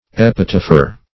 Epitapher \Ep"i*taph`er\